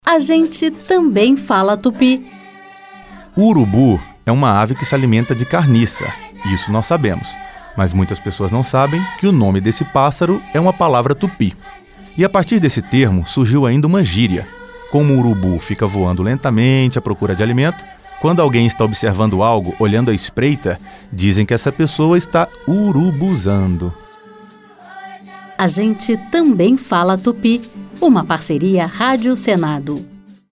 A Rádio Senado preparou o sexto grupo de dez spots da série “A gente também fala tupi”.